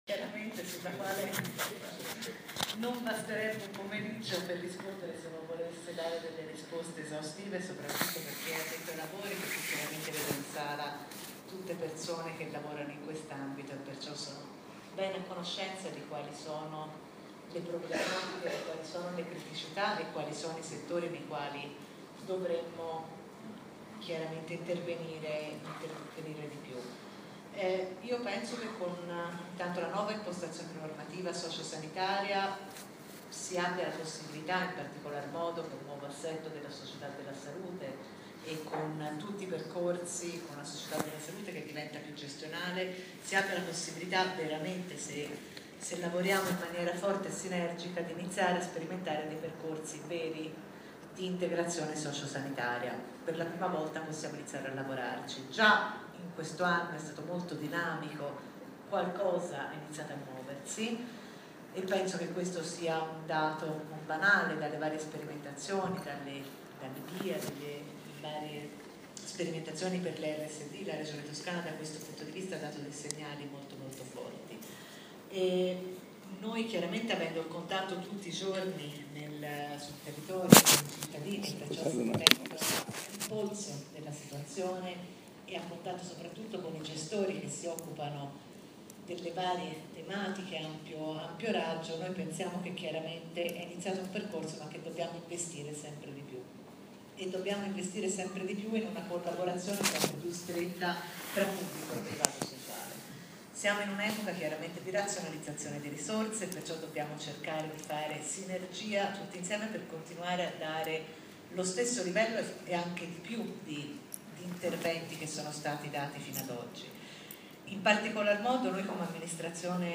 Si è tenuta ieri a Firenze, presso l’Auditorium de La Nazione, la tavola rotonda promossa dal Centro Studi Orsa, la tavola rotonda su interpretazione autentica, semplificazioni e revisioni previste e auspicabili, riguardo al testo dell’ultima delibera regionale in materia di assistenza sociosanitaria.
Ne riportiamo fedelmente gli interventi registrati, scaricabili e ascoltabili in formato mp3: